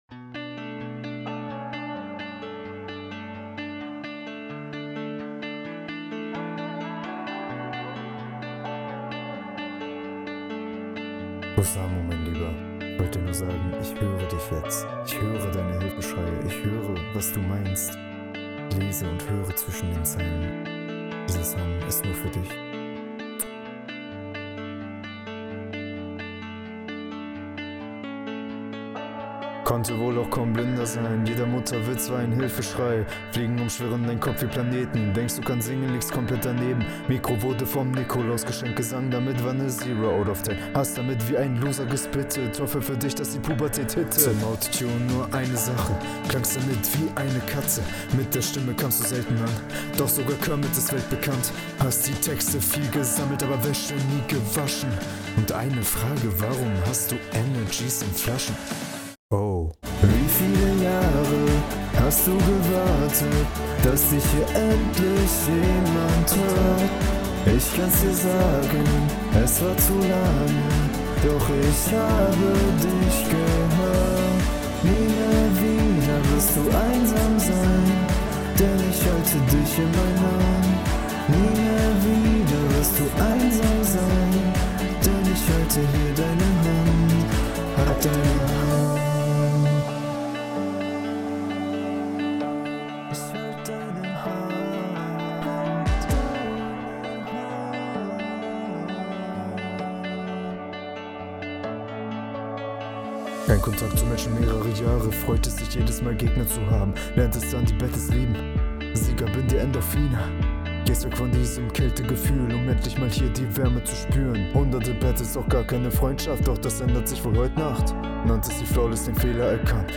Flow ist leider im vergleich zu deinem Gegner ziemlich wackelig, bleib dran.
Sehr schöner Beat, ich hab eine Hammerhook erwartet und dann... naja...